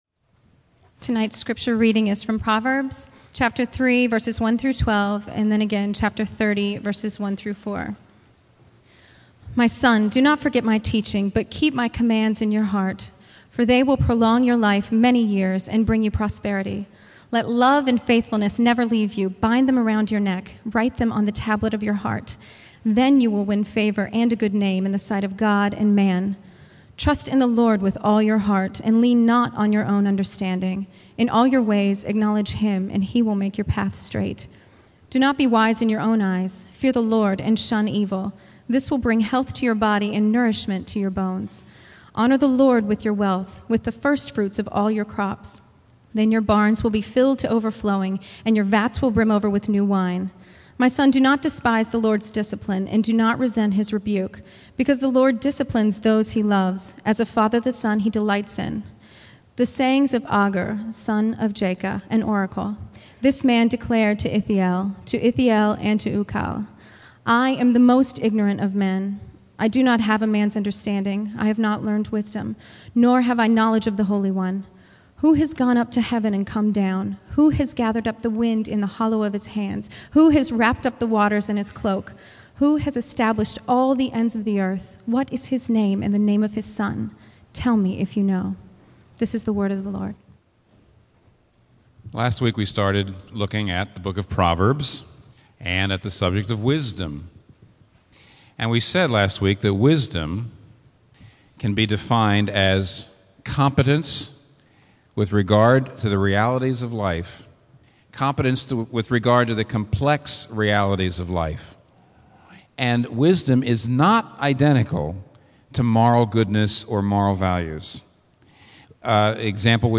Sermons | Local Church